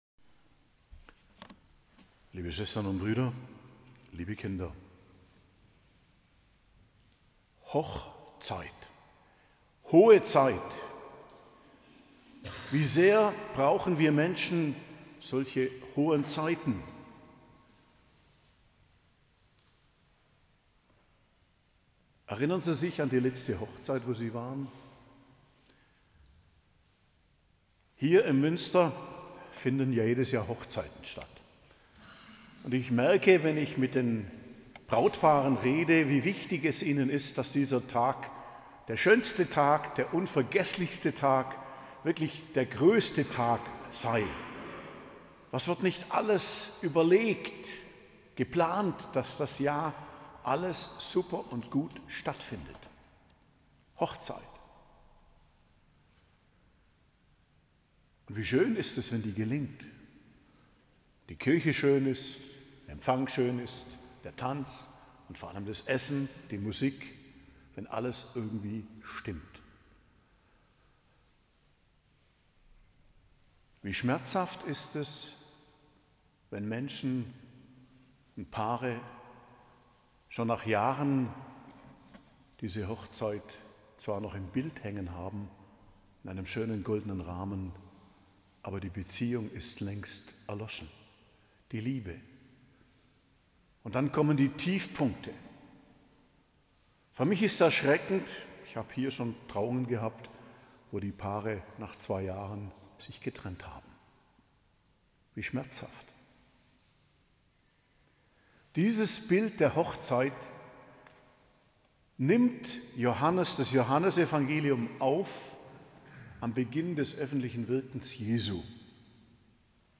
Predigt zum 2. Sonntag i.J. am 19.01.2025 ~ Geistliches Zentrum Kloster Heiligkreuztal Podcast